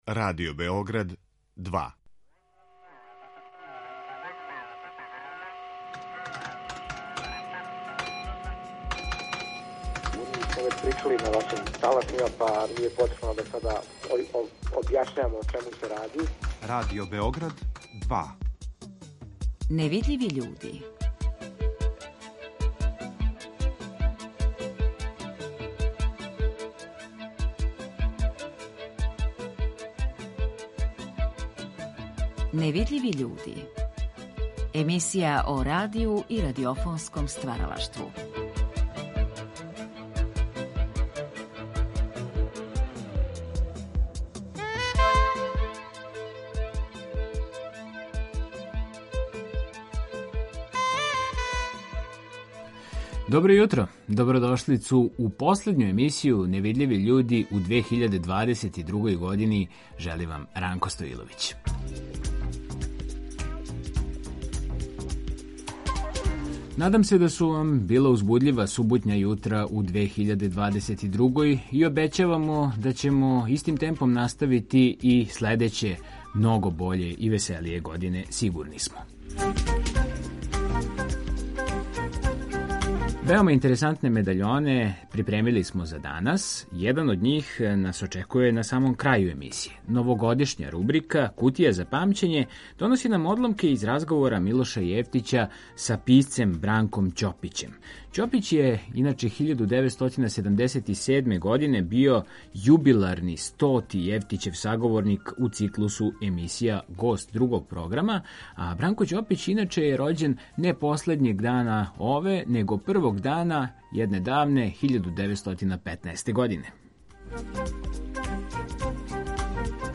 Новогодишња рубрика „Кутија за памћење" доноси нам одломке из разговора Милоша Јевтића са писцем Бранком Ћопићем .